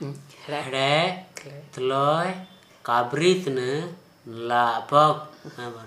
Dialect: Hill